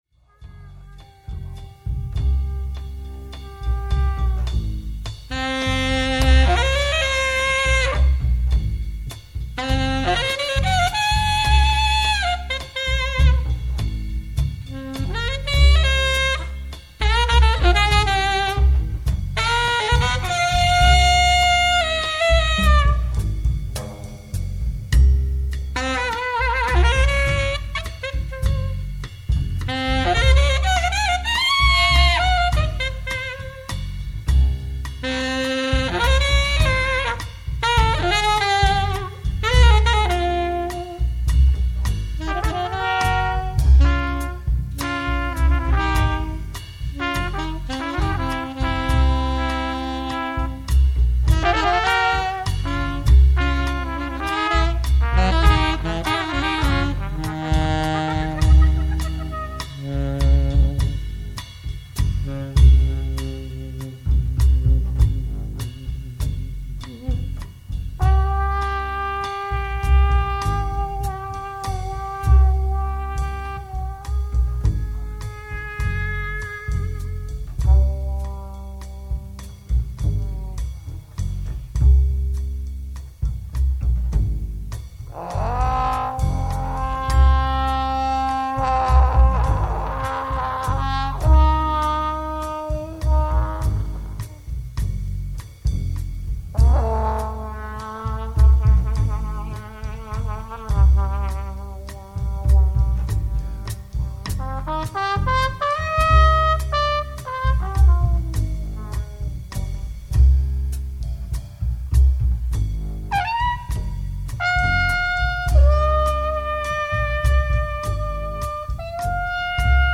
at Atlantis Studio, Stockholm, Sweden.